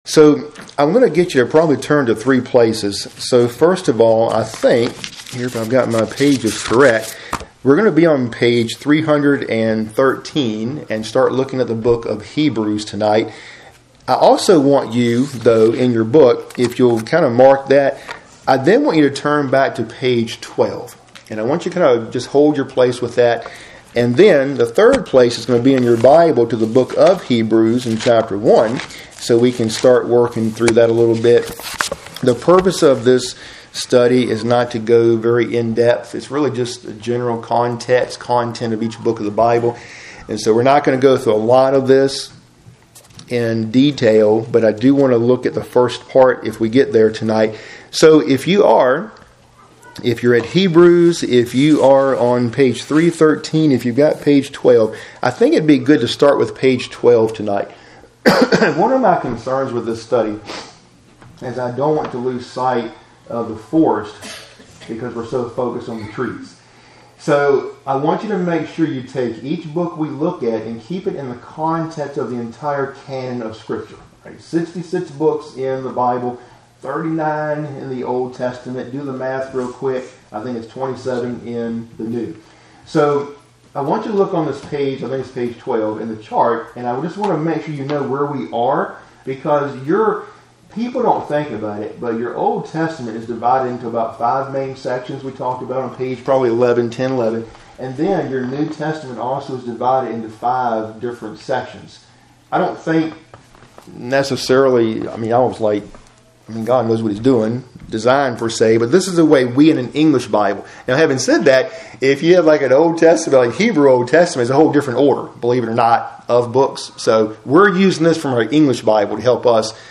Midweek Bible Study – Lesson 62